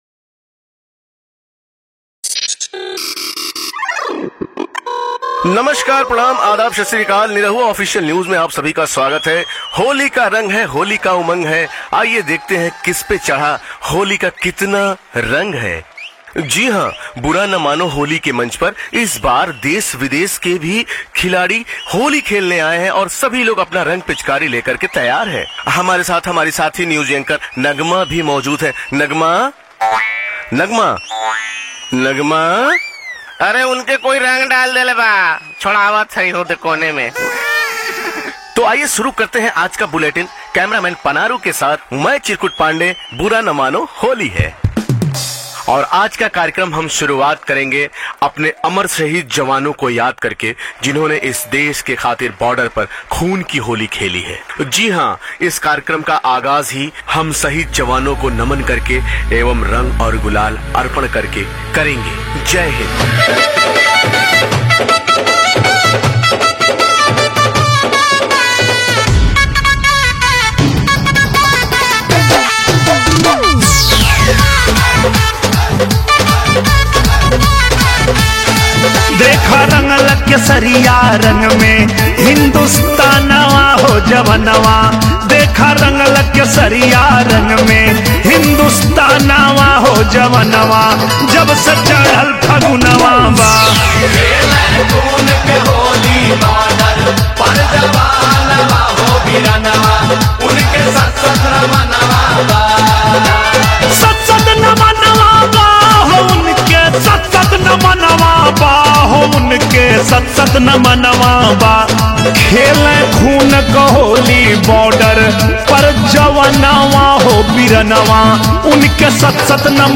Bhojpuri Holi Mp3 Songs